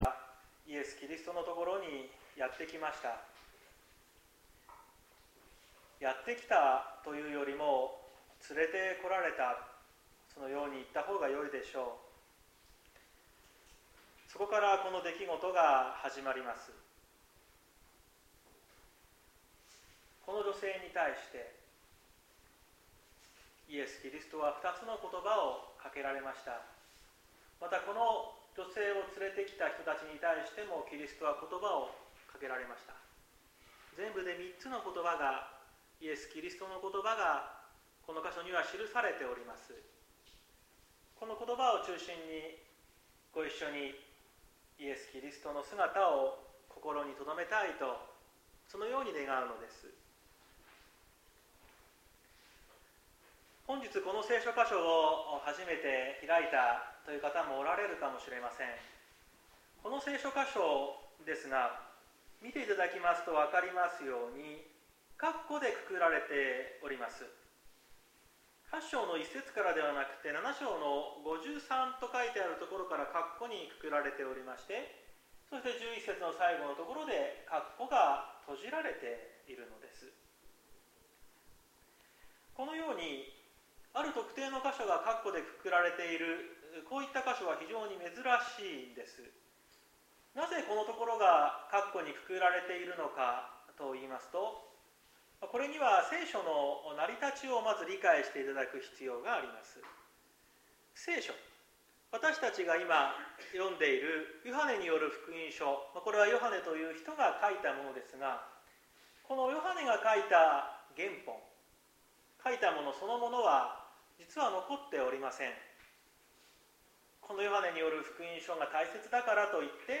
2023年09月24日朝の礼拝「むなしさのただ中におられるイエス」綱島教会
説教アーカイブ。